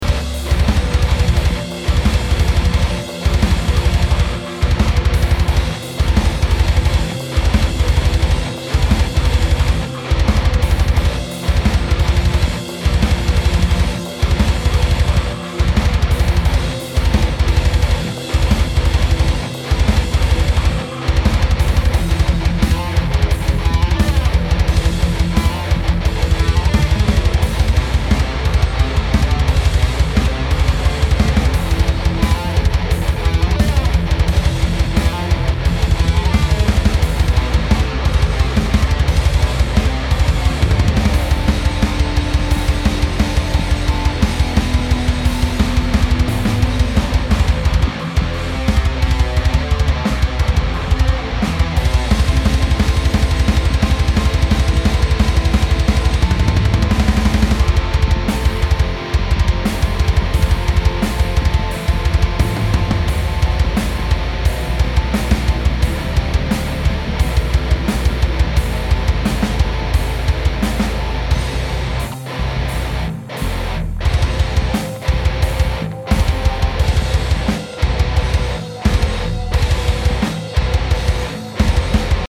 Djent/PodFarm/markbass/metalfoundry